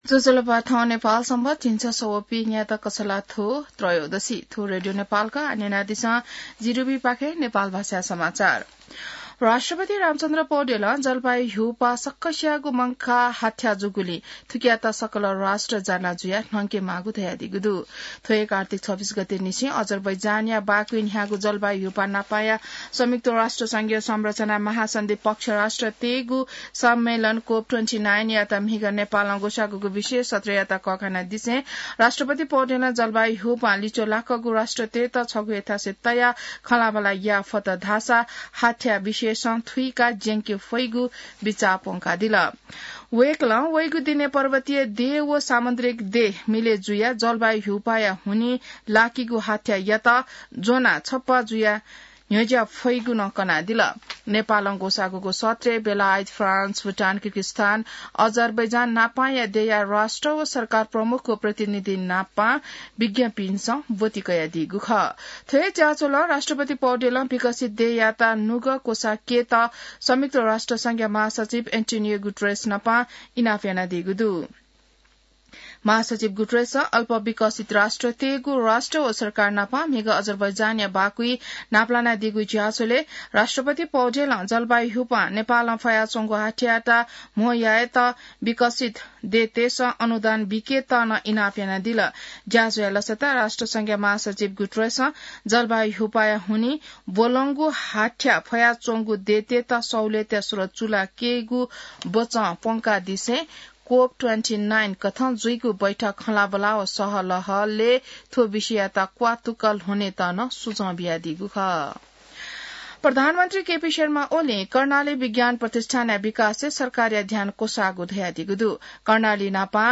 नेपाल भाषामा समाचार : ३० कार्तिक , २०८१